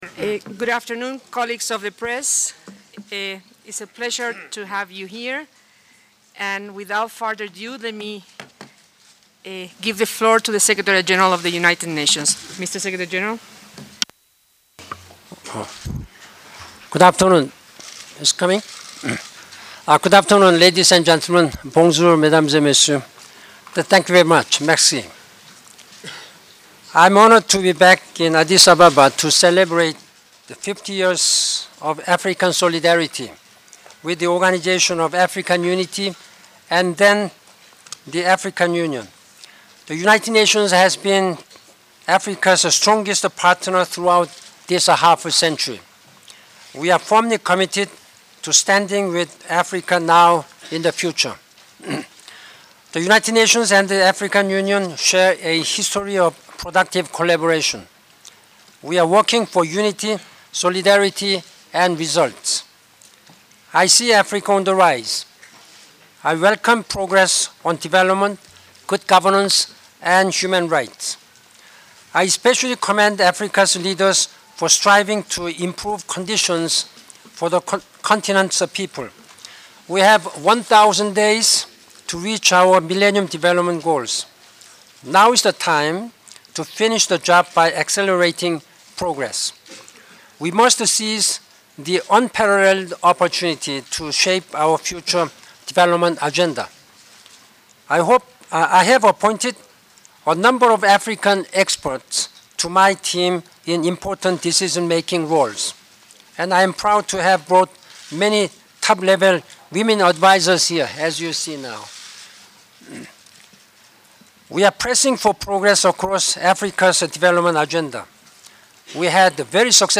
Press Conference on 50th year anniversary of African Union
Audio for banki moon Press Confrence.mp3